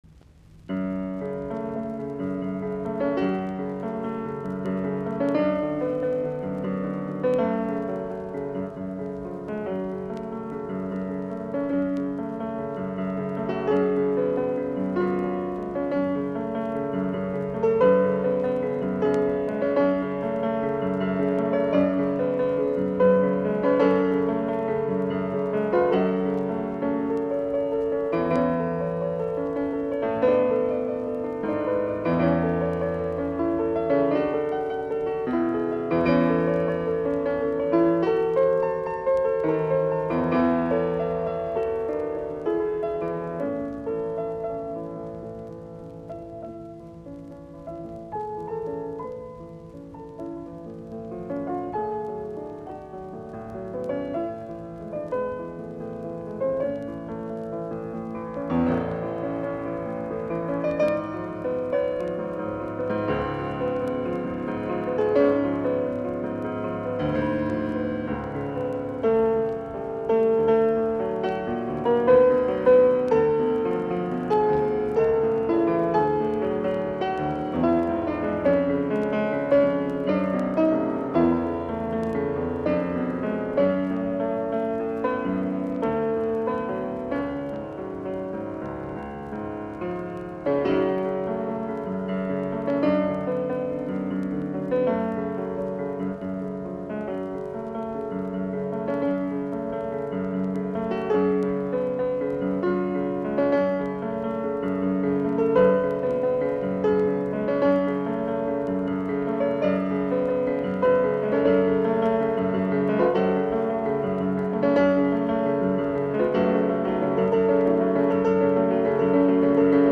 Sonaatit, piano, nro 1, op11, fis-molli
Poco adagio - Allegro vivace
Soitinnus: Piano.